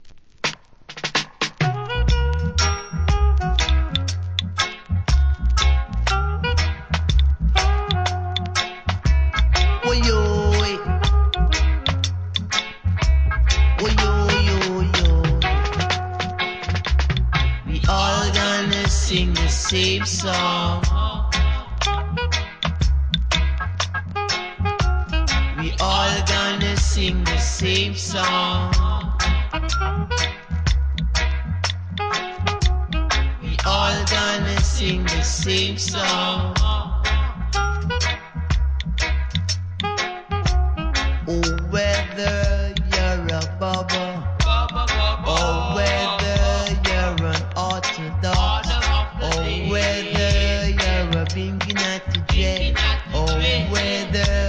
REGGAE
優しく歌い上げるROOTSデュオ名作!!